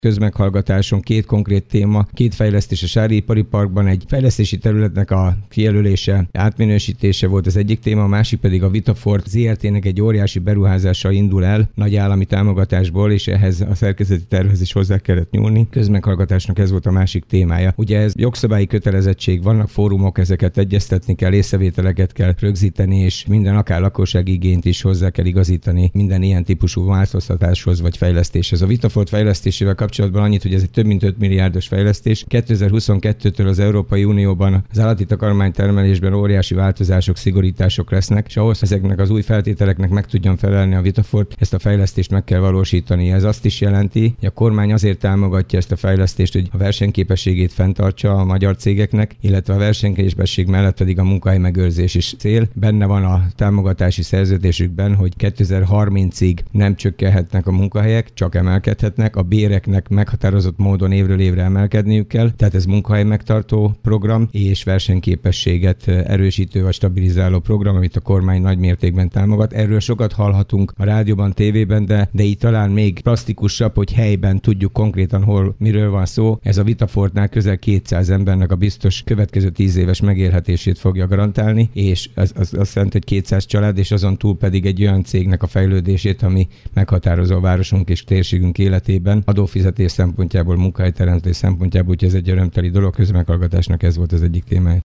Változik Dabas szerkezeti terve a közeljövőben. Ennek egyik oka a sári ipari parkban történő beruházás, a másik pedig a Vitafort Zrt. nagy volumenű beruházása. Ez volt a témája a múlt heti közmeghallgatásnak is, amelynek részleteiről Kőszegi Zoltán polgármestert hallják.